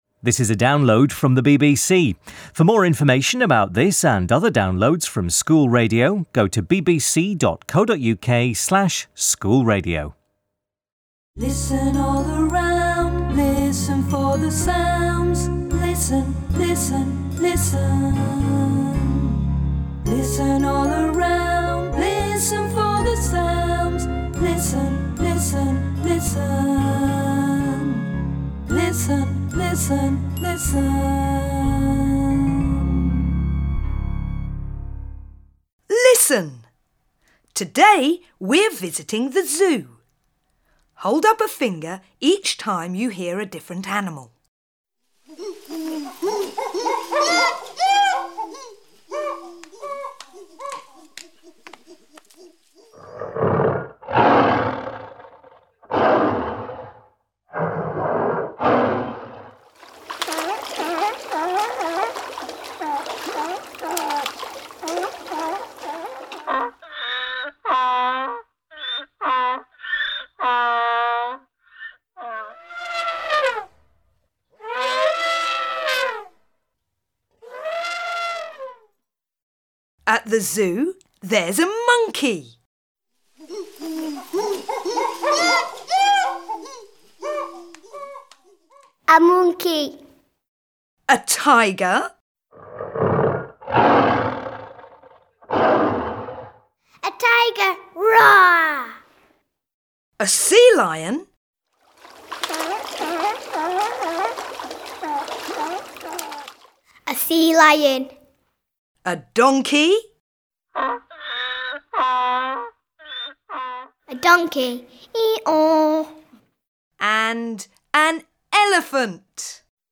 14_sound_games_3.mp3